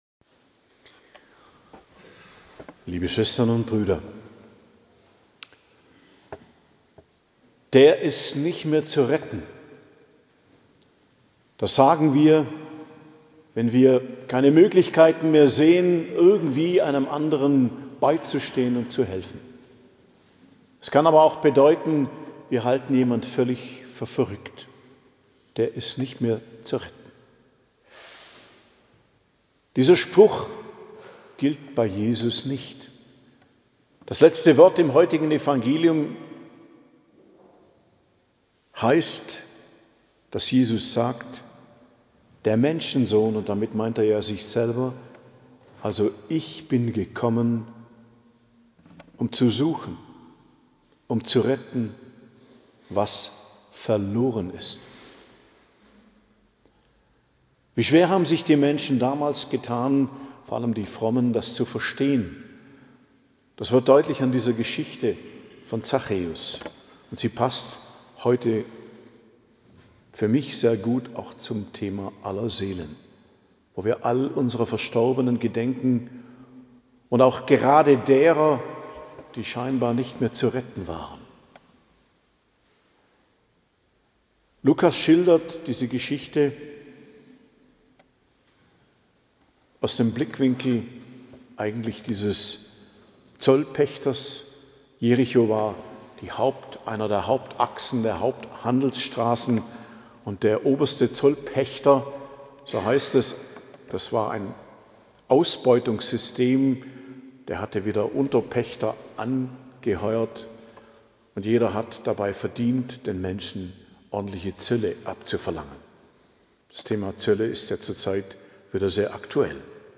Predigt zum 31. Sonntag i.J., Allerseelen, 2.11.2025 ~ Geistliches Zentrum Kloster Heiligkreuztal Podcast